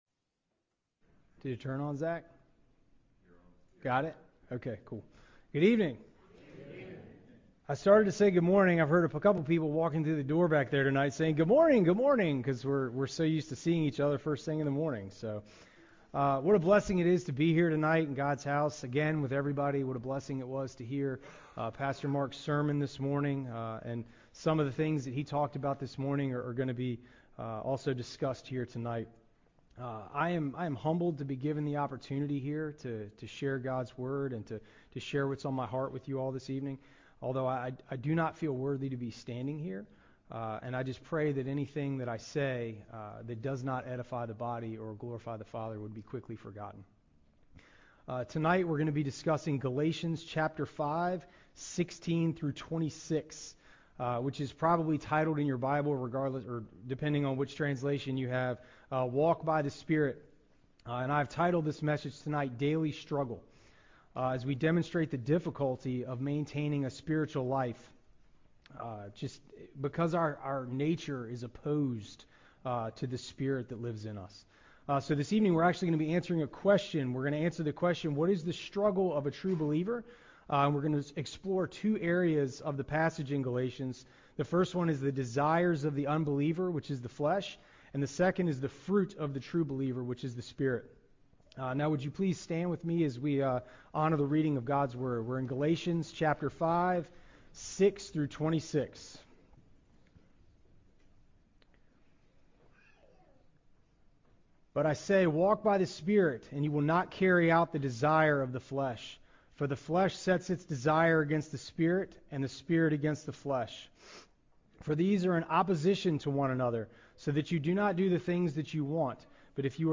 Sunday Night Teaching